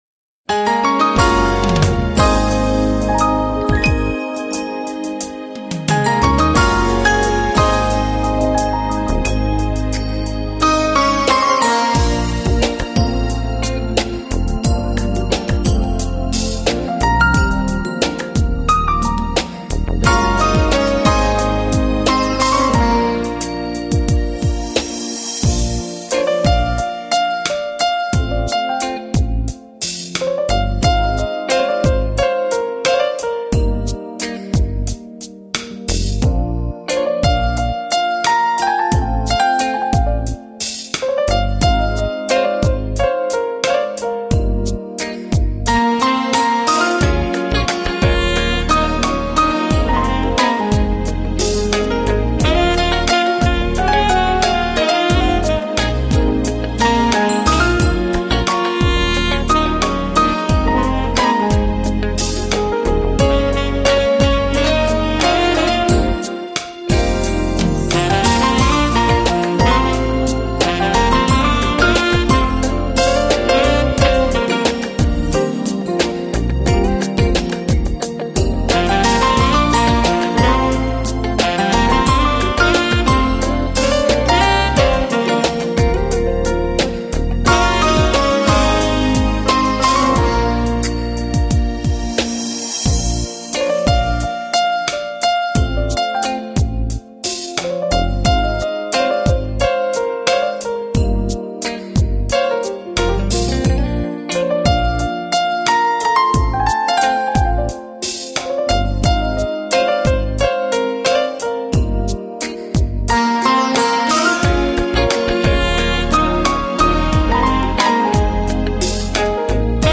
音乐风格:Smooth Jazz